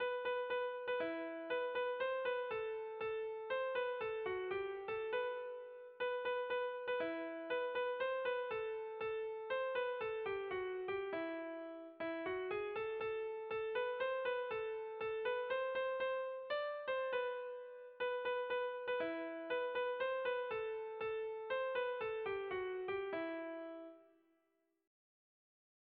Bertso melodies - View details   To know more about this section
Irrizkoa
Zortziko handia (hg) / Lau puntuko handia (ip)
A-A2-B-A2